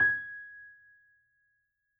Added more instrument wavs
piano_080.wav